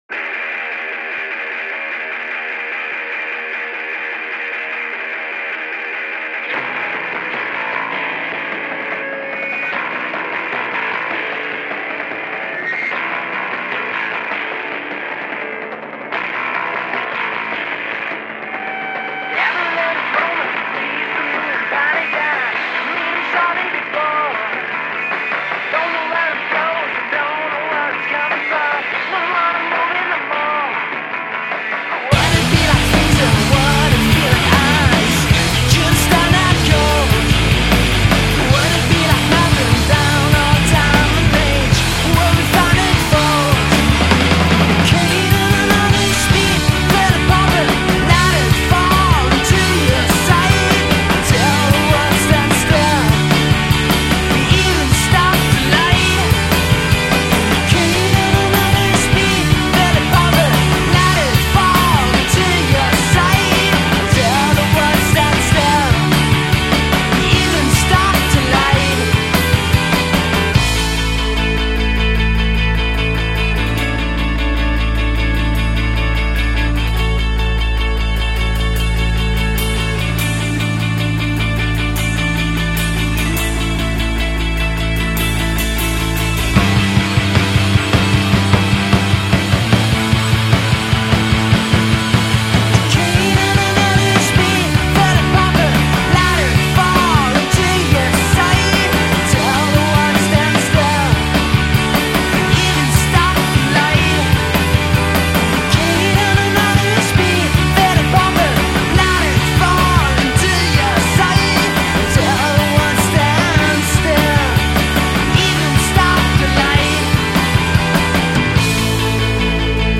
Alternative/Independent